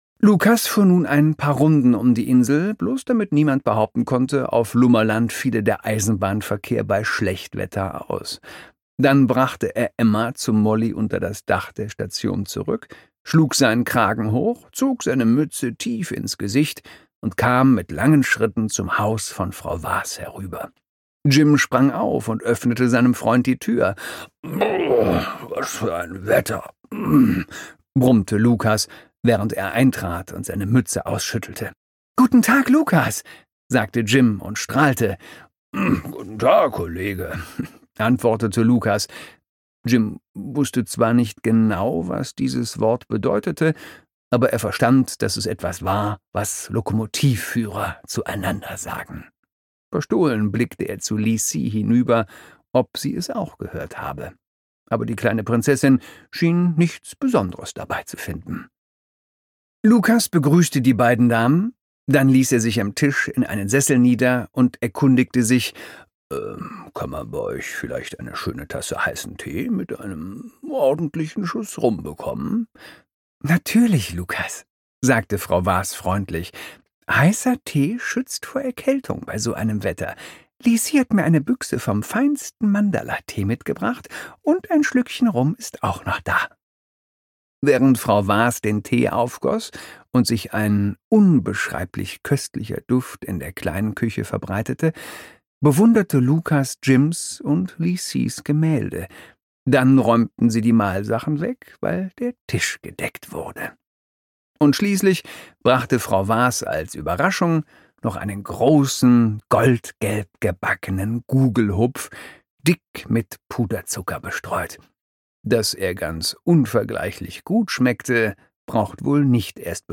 Jim Knopf: Jim Knopf und die Wilde 13 - Die ungekürzte Lesung - Michael Ende - Hörbuch